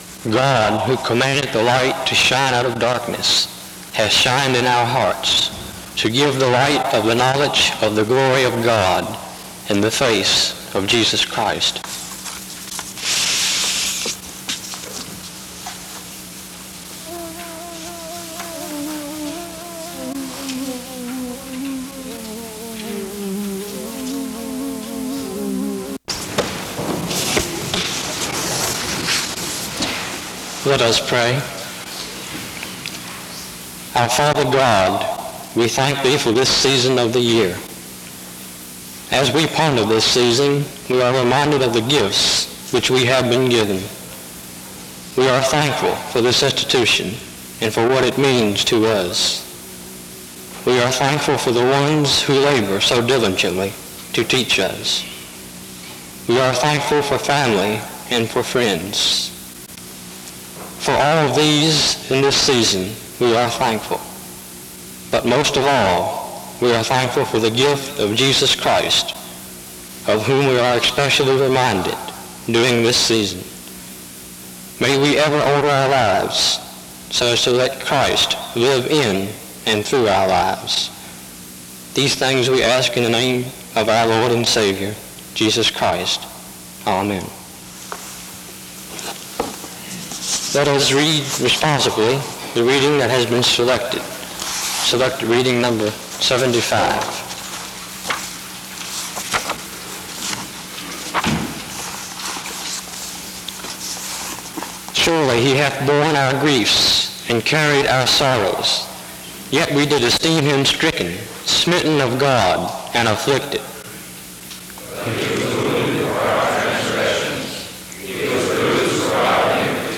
Download .mp3 Description The service begins with an opening scripture reading and music from 0:00-0:26. A prayer is offered from 0:30-1:27. A responsive reading takes place from 1:30-3:20. A message on John 1:18 is given from 3:44-9:14. A call to remember Jesus during the busy Christmas season is given from 9:24-11:44.